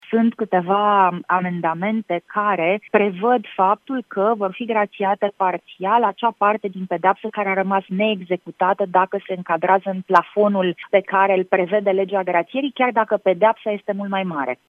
Senatoarea Alina Gorghiu, membră a Comisiei Juridice din Senat, a declarat la Europa FM că ”grațierea a devenit o obsesie” pentru PSD.